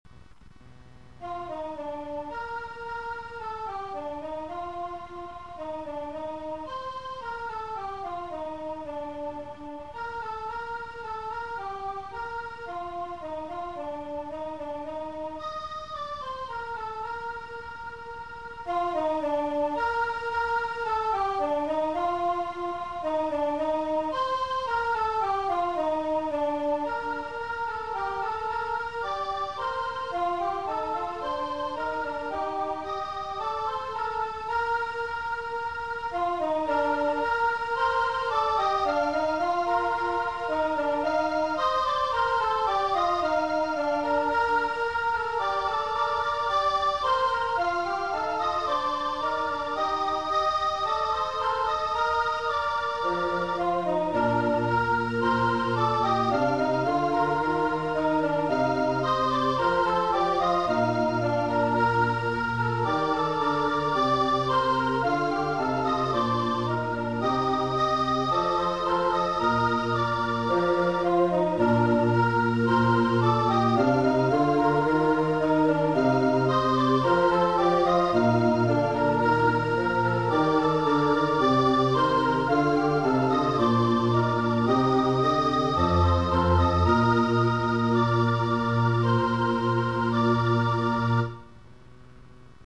Non nobis - Base.mp3